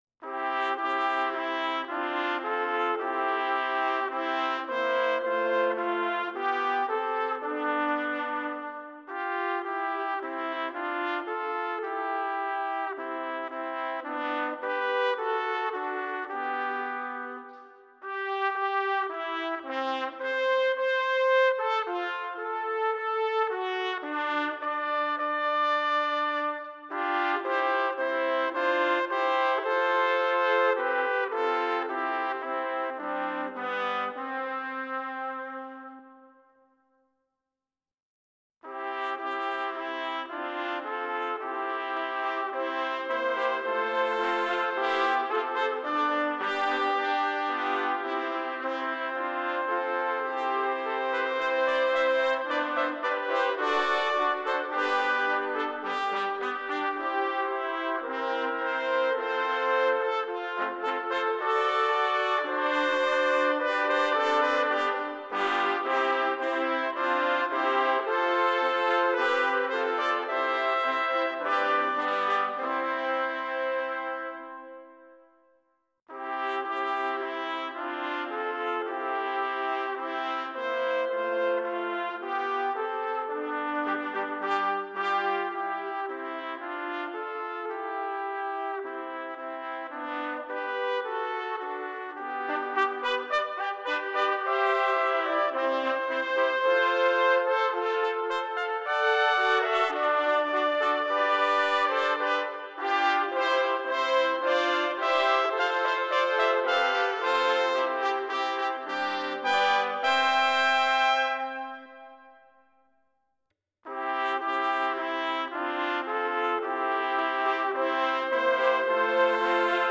Number of Trumpets: 3
Exhilarating, majestic, and inspiring.